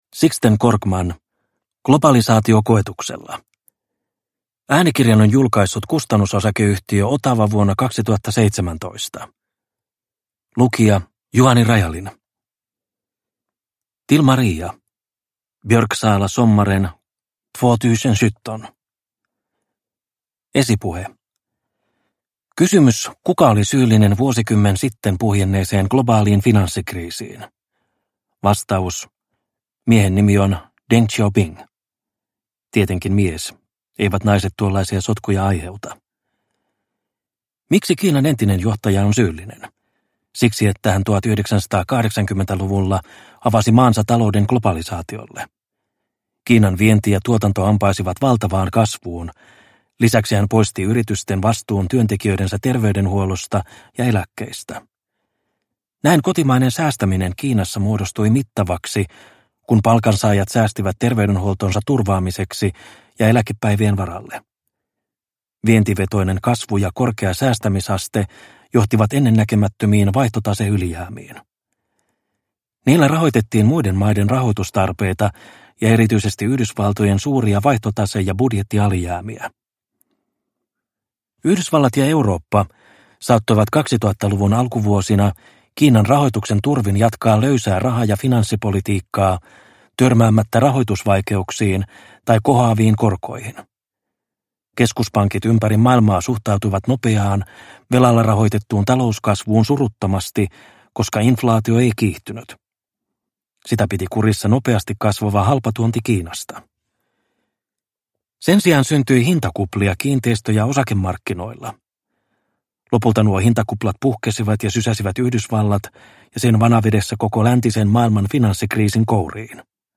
Globalisaatio koetuksella – Ljudbok – Laddas ner